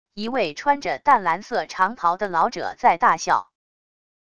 一位穿着淡蓝色长袍的老者在大笑wav音频